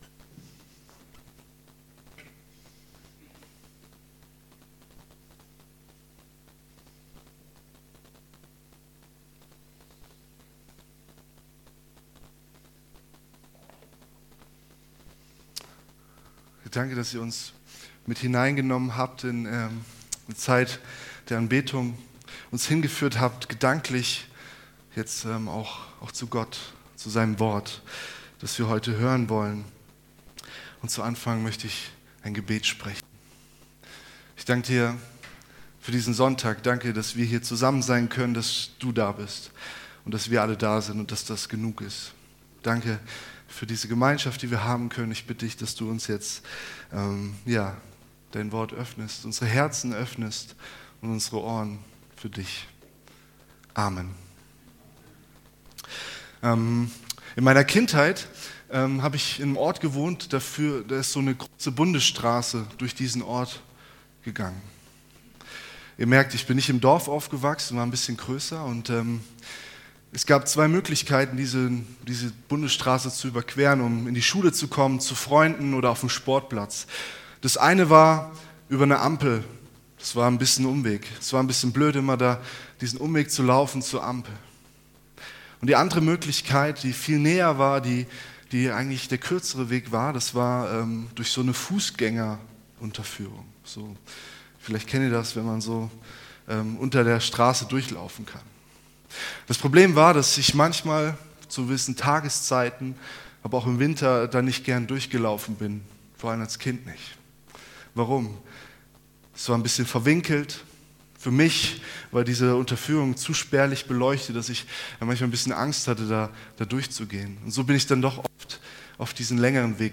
Predigtserie Jesus Begegnen – FeG Waigandshain